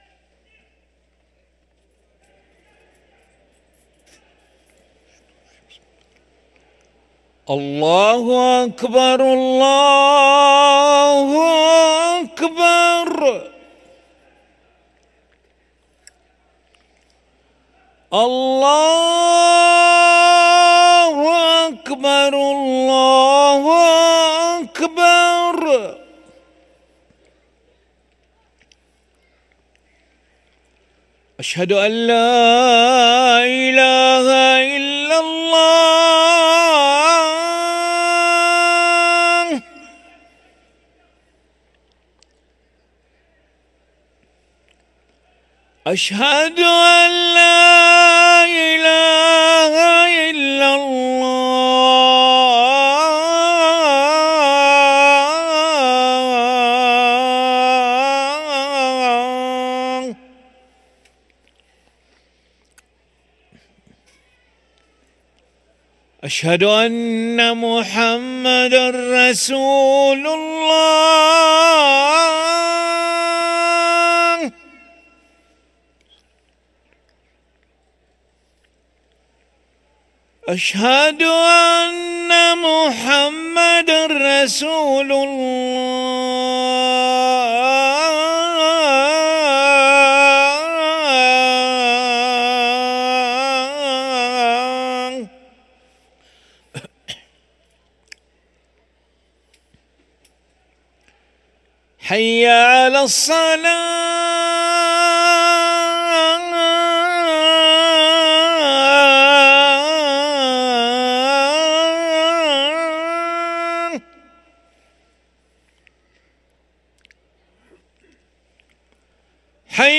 أذان العشاء للمؤذن علي ملا الخميس 6 ربيع الأول 1445هـ > ١٤٤٥ 🕋 > ركن الأذان 🕋 > المزيد - تلاوات الحرمين